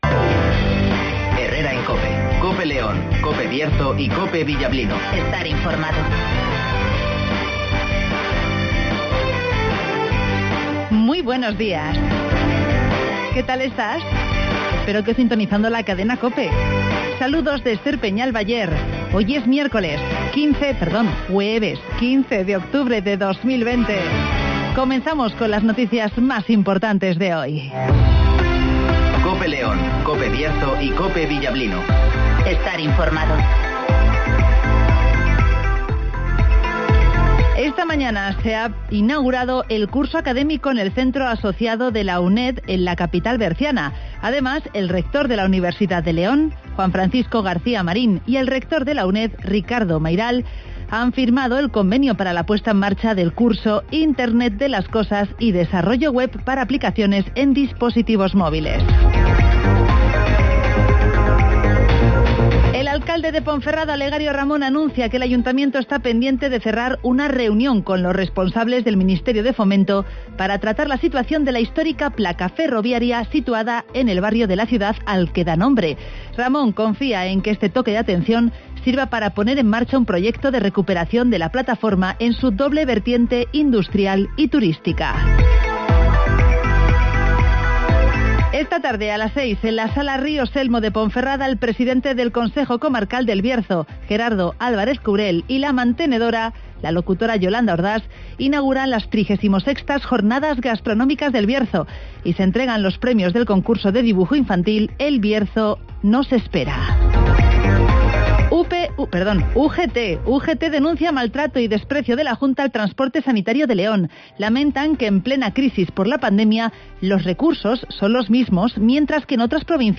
-Avance informativo